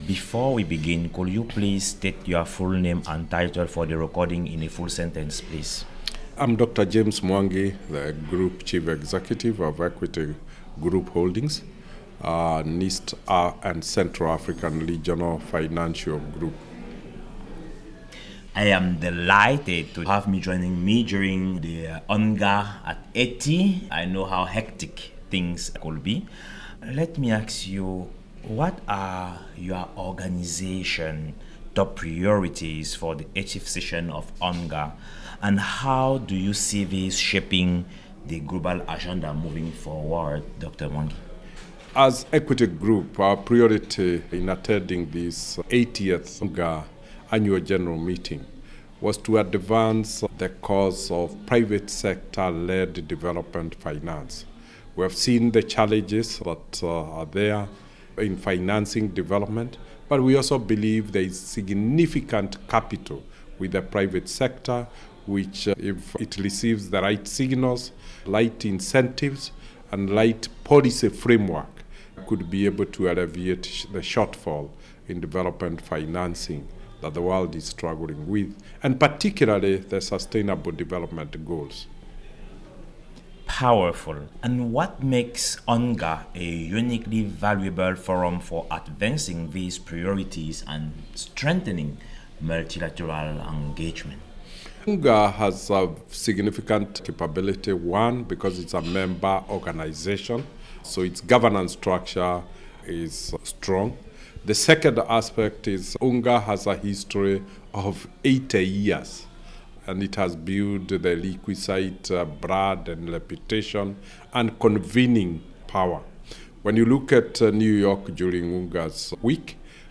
Foresight Africa podcast at UNGA 2025